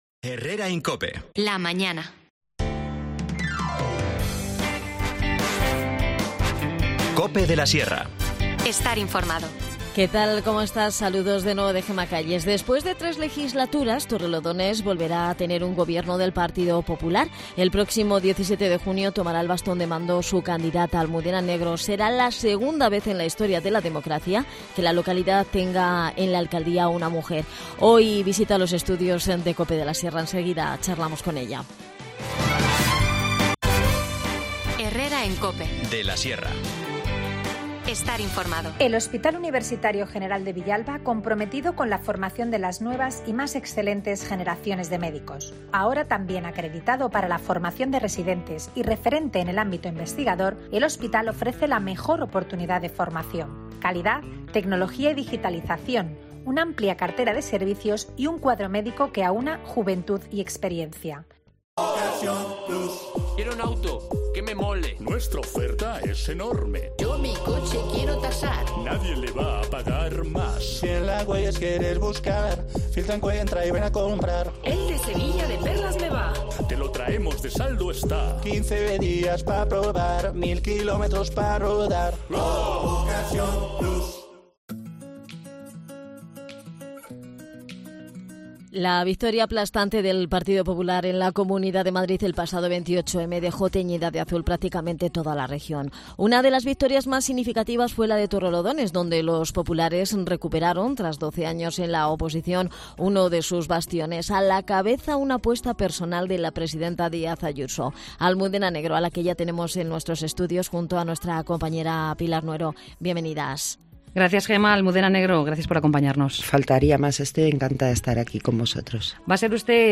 En su visita a los estudios de Cope de la Sierra, Almudena Negro ha dado más de un titular, asegurando que no habrá traslado del cuartel de la Guardia Civil desde La Colonia, tal y como se comprometió en campaña electoral; que lo primero que hará será una auditoría de las cuentas municipales porque no se fía de la gestión de Vecinos por Torrelodones y que las obras para la construcción del ansiado aparcamiento en la estación de Cercanías comenzarán en 2024.